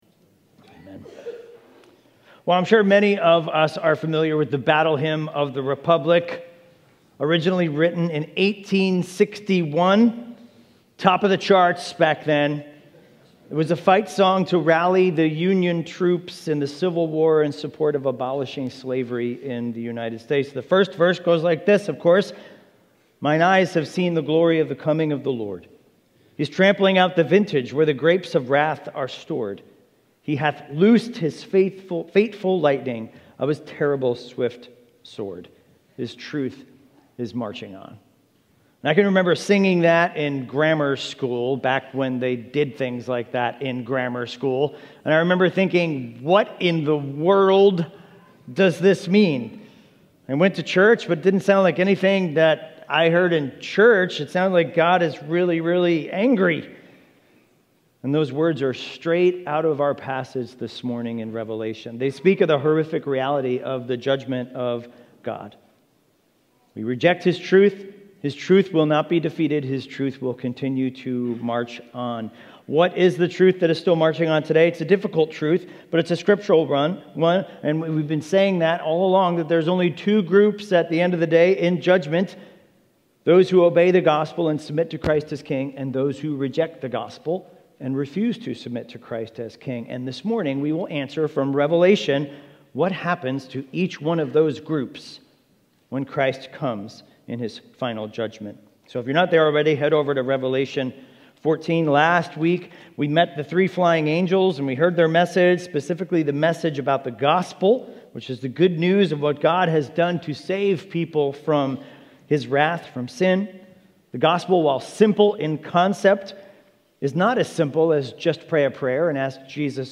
In Reveation 14:14-20 we learn that God's people have the assurance of salvation, others have the reality of judgment. NOTE: The audio drops out for a few minutes, but returns due to an issue with the micrphone during the service.
Expositional preaching series through the book of Revelation.